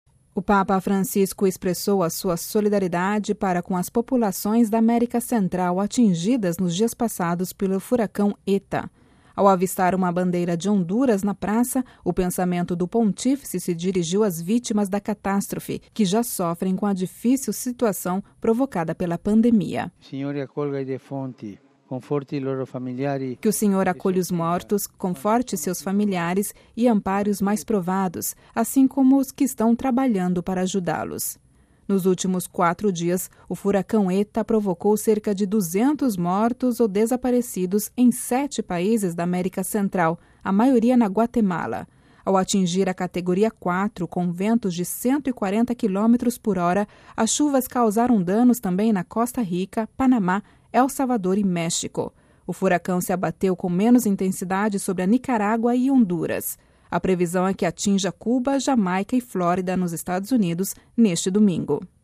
Ouça a reportagem completa com a voz do Papa Francisco